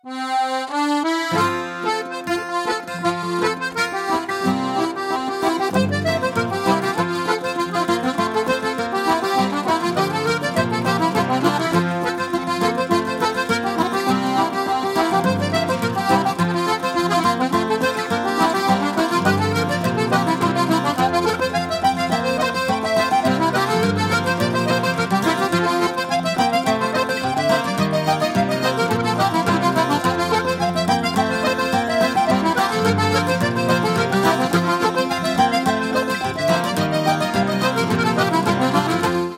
fiddle/vocals
accordion
flute/whistle
guitar/bouzouki
bodhrán (Irish drum)
rhythmically diverse traditional tunes of more modern origin